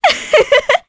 Juno-Vox_Happy2_jp.wav